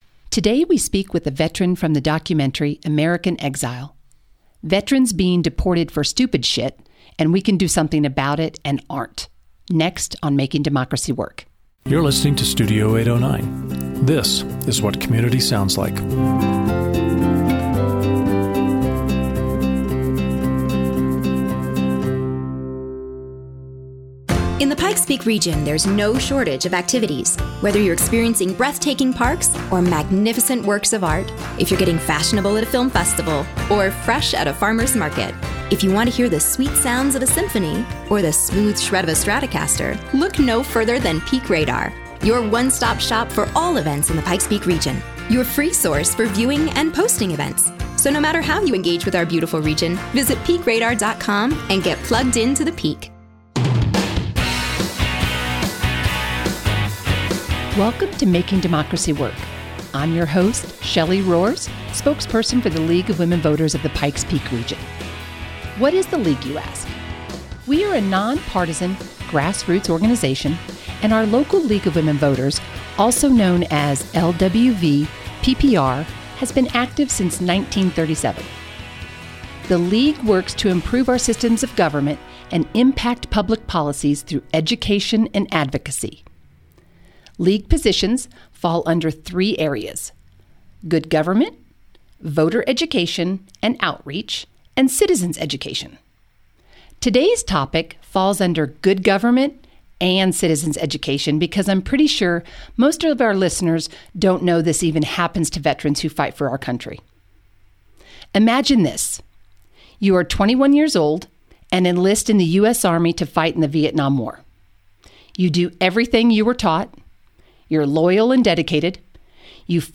Veterans are being deported, and we can do something about it, but aren’t. A veteran from the documentary American Exile shares his story.